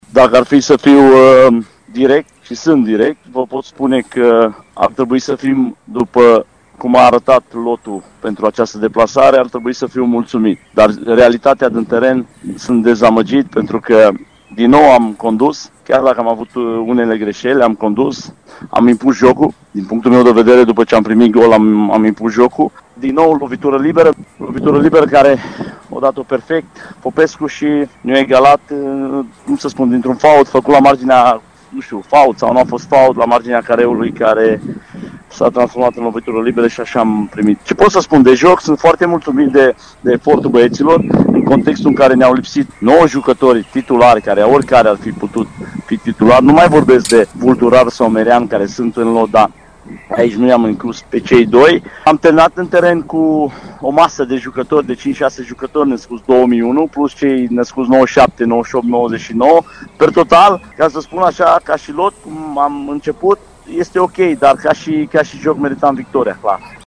Avem în continuare reacții din cele două tabere.